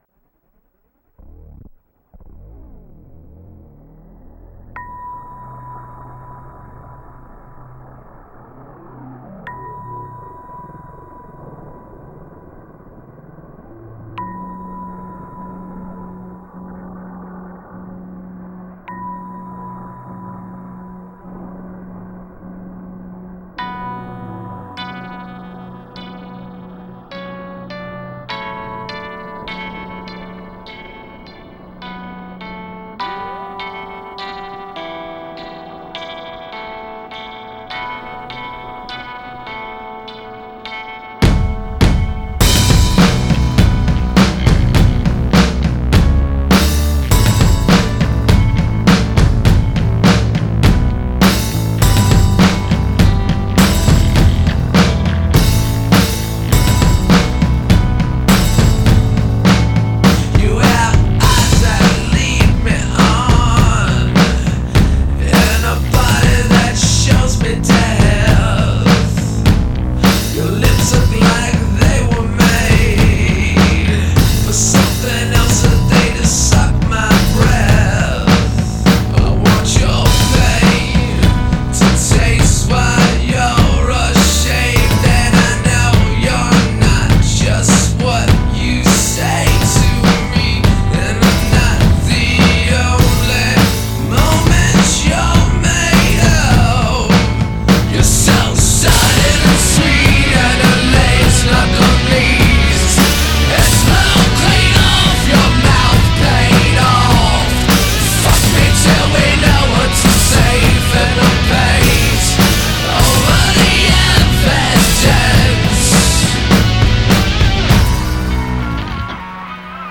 Tags: Rock Songs Musik